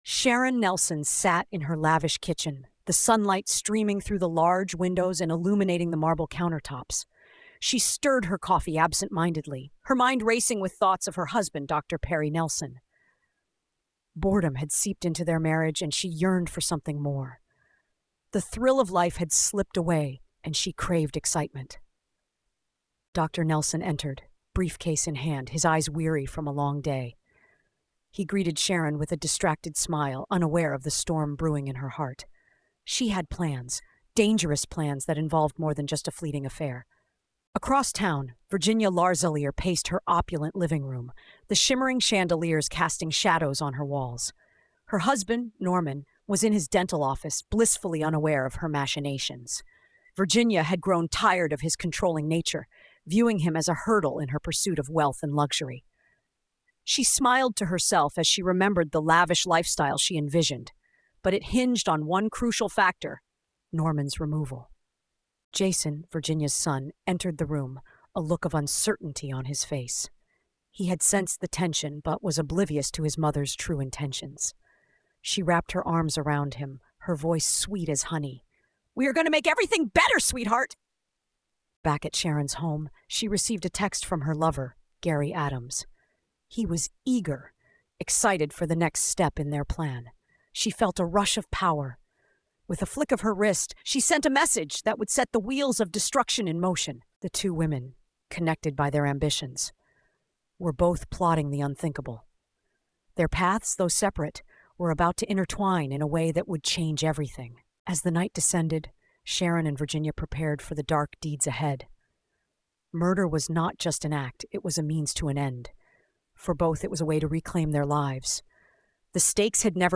The atmosphere is tense, intimate, and forensic — a descent into the minds of women who believe they are above consequences, and the investigation that proves they aren’t.